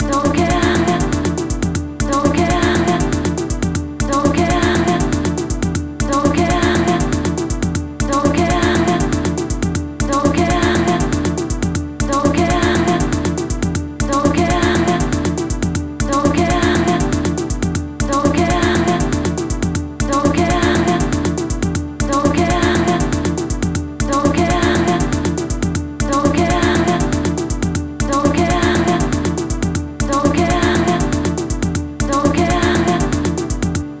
and with some lead and vocals